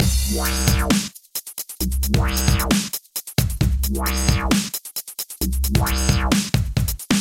Here’s how the patch sounds with the filter added – the combination of two parameters both moving to the same LFO is already starting to add some nice dynamic to the sound: